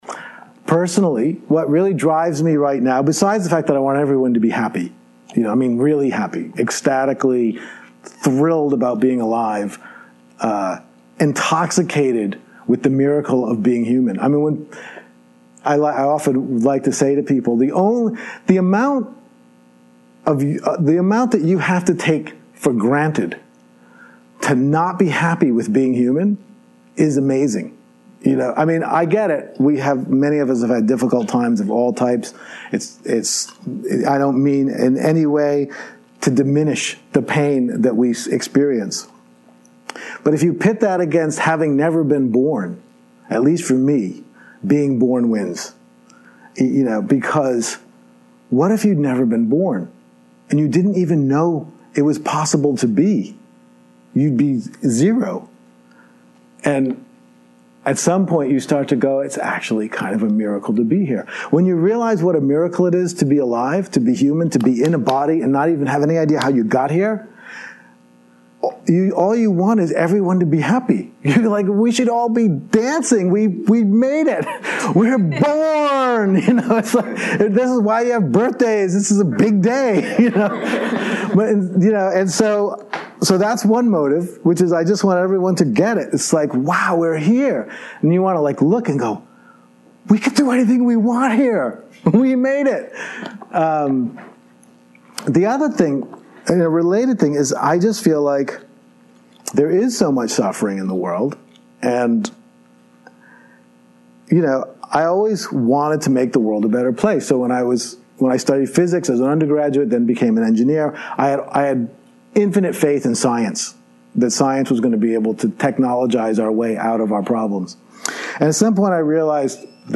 Here I want to share an audio clip that was recorded during a retreat I lead for undergraduate students at Purchase College in New York.